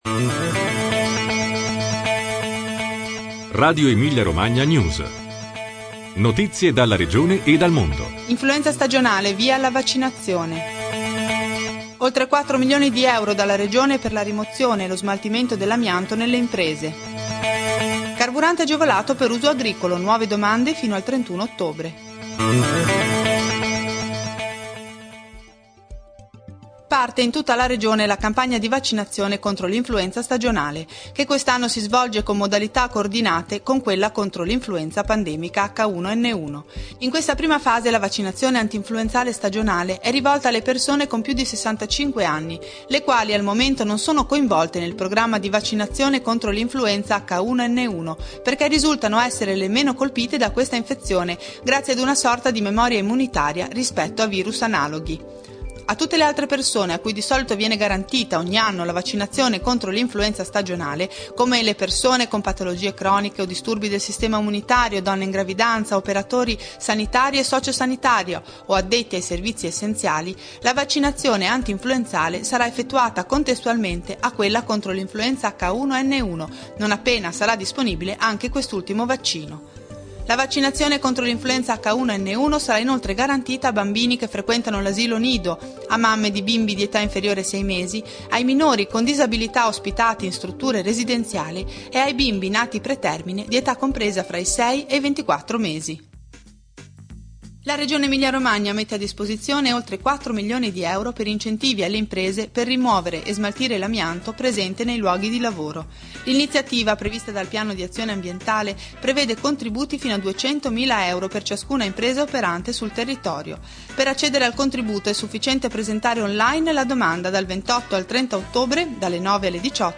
News dall'Emilia-Romagna e dal mondo